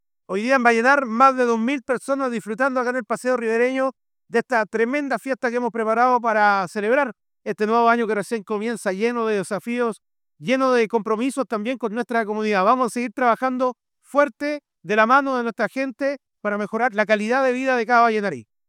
AUDIO-ALCALDE-enhanced.wav